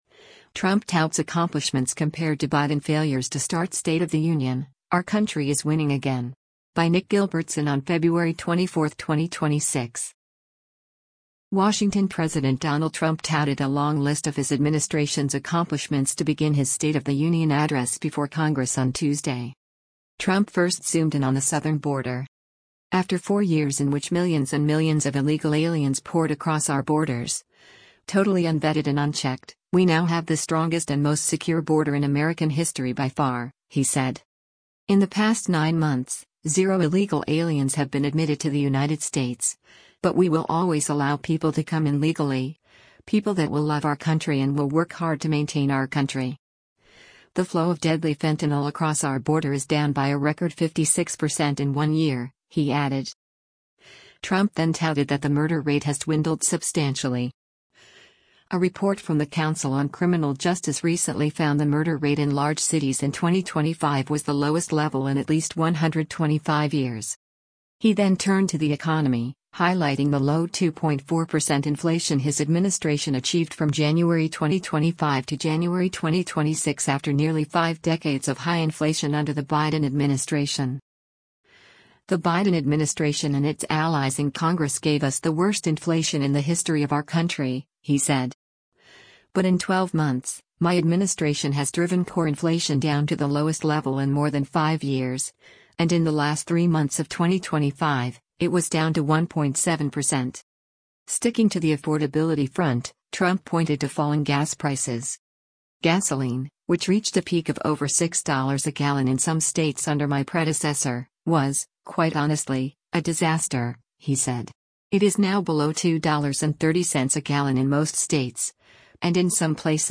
WASHINGTON–President Donald Trump touted a long list of his administration’s accomplishments to begin his State of the Union address before Congress on Tuesday.